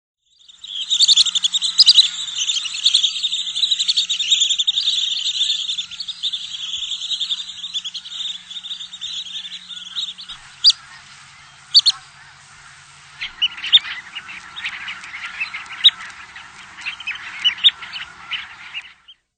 Long-billed Dowitcher
Limnodromus scolopaceus
Bird Sound
Flight call a sharp "peep," or quick series of two to five notes, "pee-p-p-pep."
Long-billedDowitcher.mp3